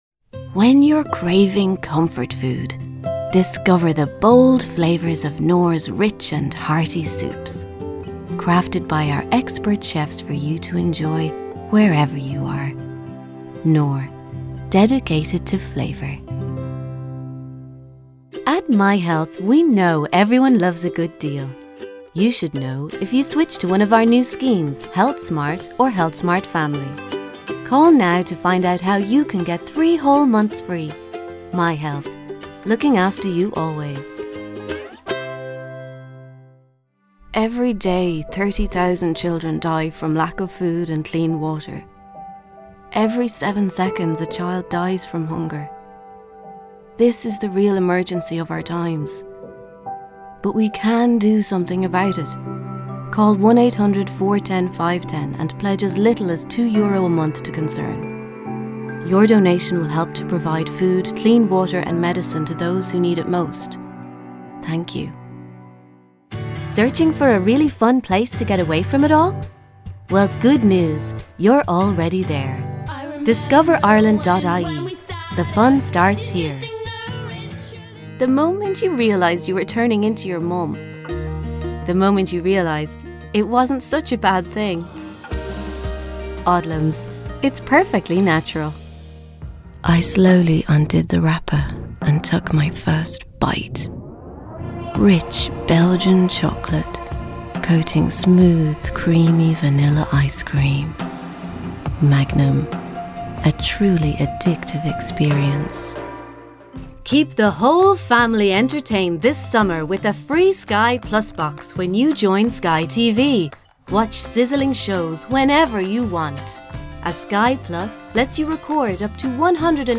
Female
Sure SM7B Mic, Scarlett 4i4 Audio Interface, FMR Audio 'Really Nice Compressor', Logic Pro/Pro Tools, dedicated 5G wifi, Source Connect Now, Google Meets, Skype, Zoom, Cleanfeed etc.
30s/40s, 40s/50s
Irish Midlands, Irish Neutral